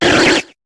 Cri de Kecleon dans Pokémon HOME.